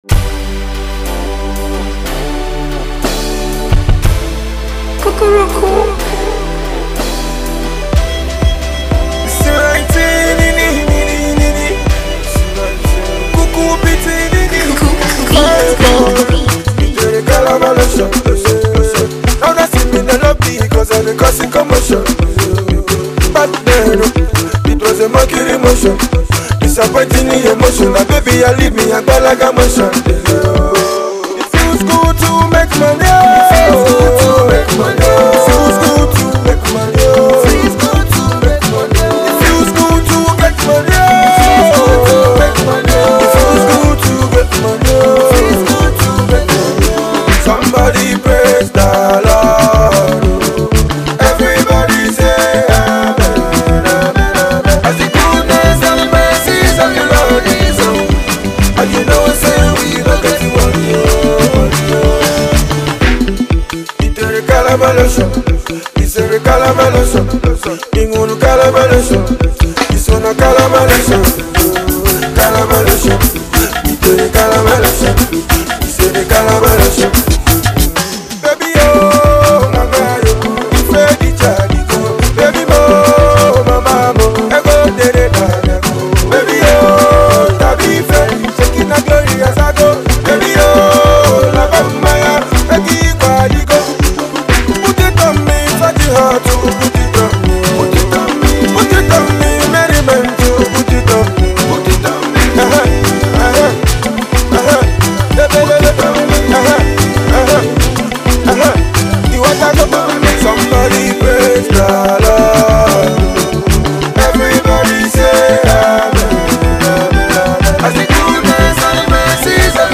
Pop song